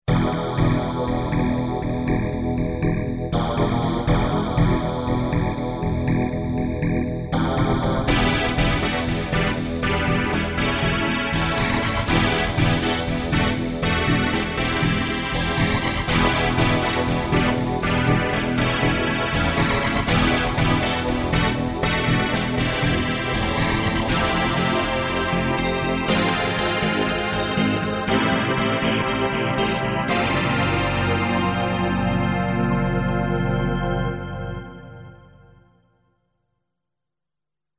A complex DX7 sound that just isn't possible to do with wave sampling and conventional filter systems. 'Dulcimer' patch (dry record).
dx7_colorsdemo.mp3